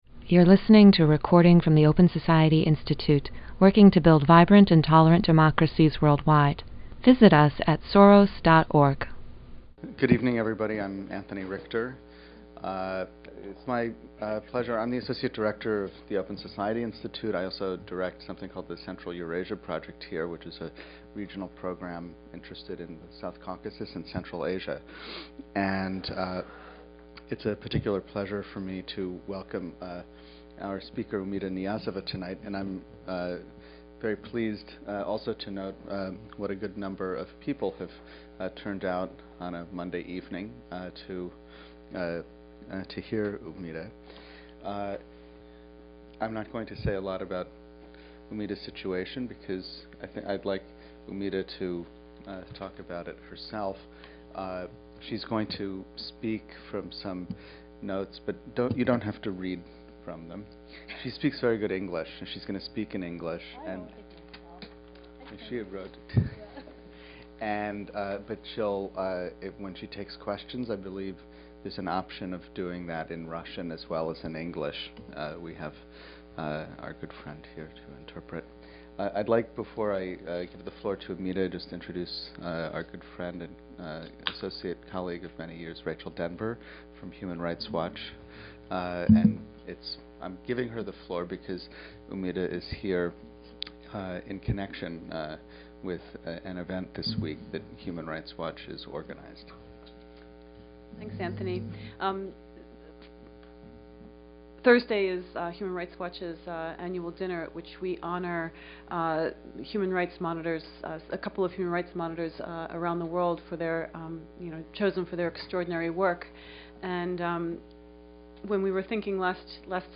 The Open Society Institute presented a discussion on human rights and civil society in Uzbekistan